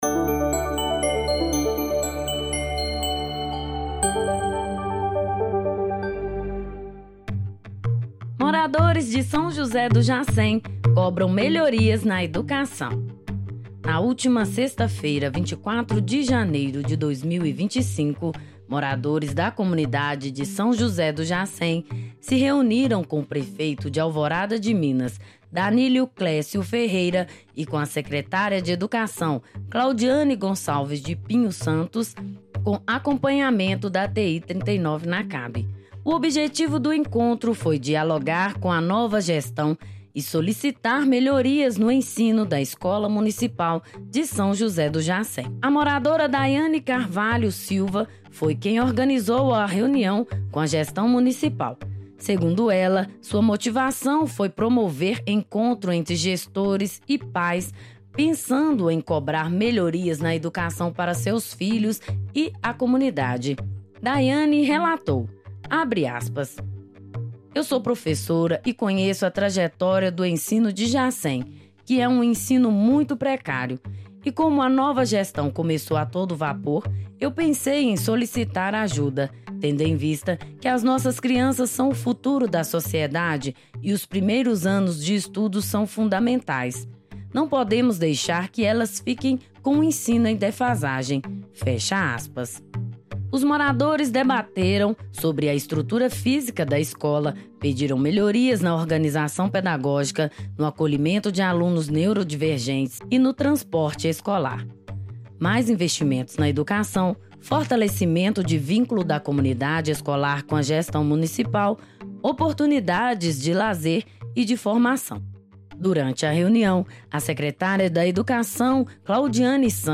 Audioreportagem-REUNIAO-COMUNIDADE-E-PREFEITURA-ALVORADA.mp3